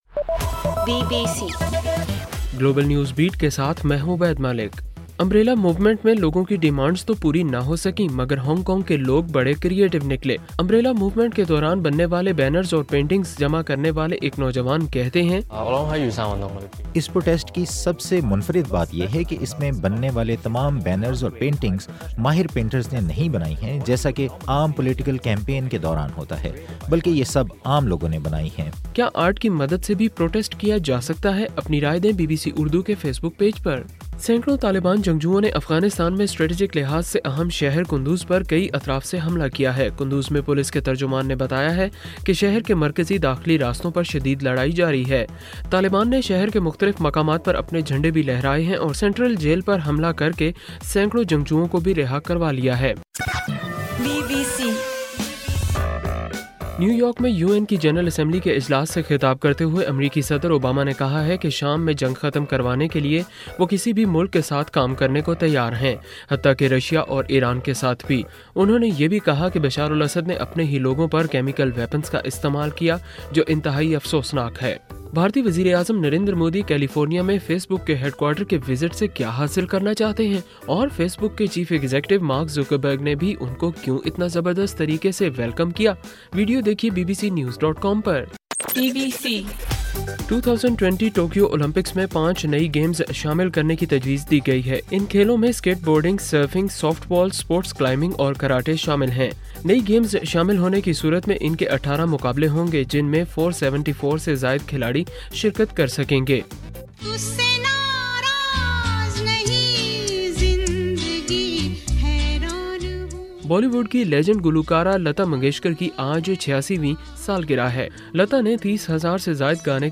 ستمبر 28: رات 11 بجے کا گلوبل نیوز بیٹ بُلیٹن